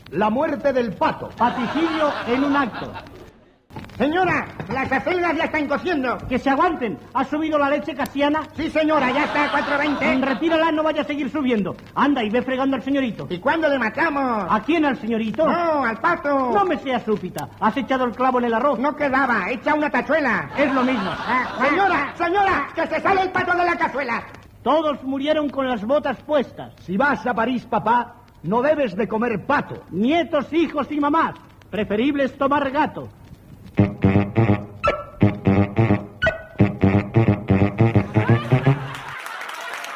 Fragment del diàleg còmic "La muerte del pato"
Entreteniment